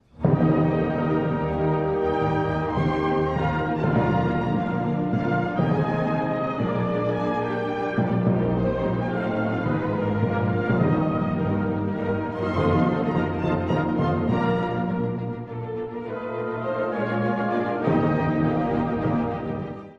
第1楽章｜黄金のように雄大な幕開け
古い音源なので聴きづらいかもしれません！（以下同様）
冒頭、金管と弦がいっせいに鳴り響き、力強く輝かしい主題が現れます。
展開部も非常に長く、構成はベートーヴェン的。
schumann-sy3-1.mp3